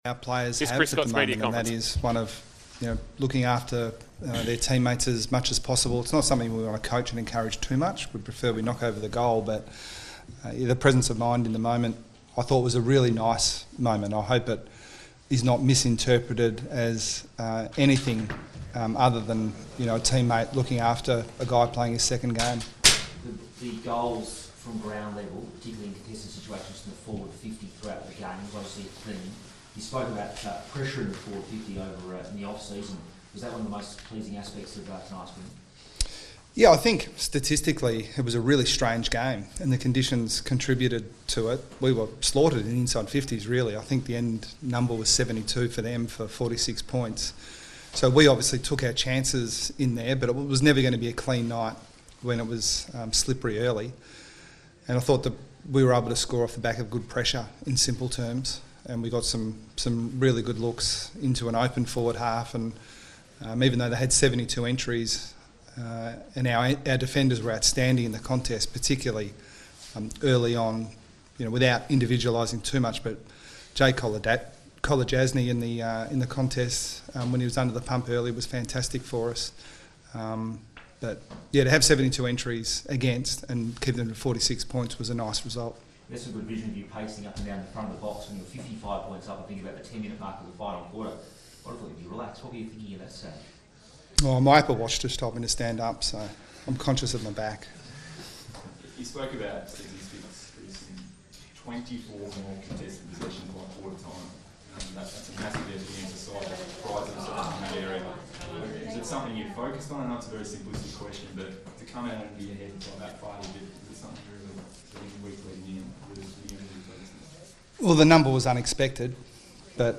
POST-MATCH: CHRIS SCOTT - Geelong Press Conference